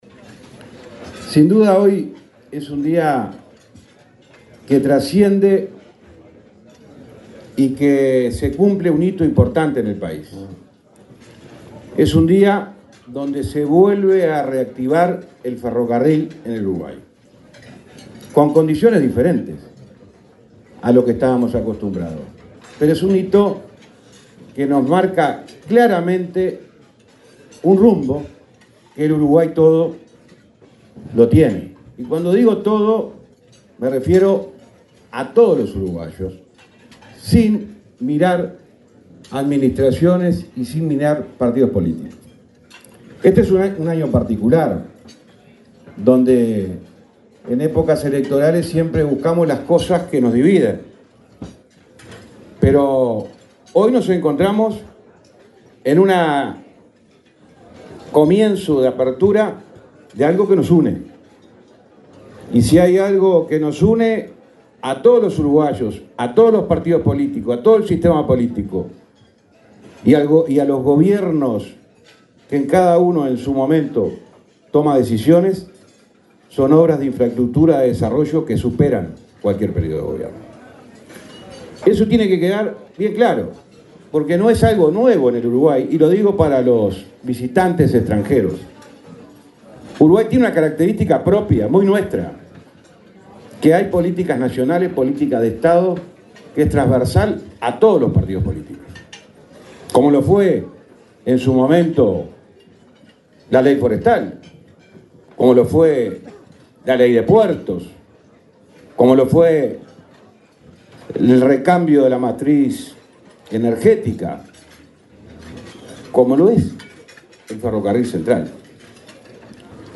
Palabras del ministro de Transporte, José Luis Falero
El ministro de Transporte, José Luis Falero, participó, este martes 16 en la localidad de Cardal, en Florida, en el acto de implementación del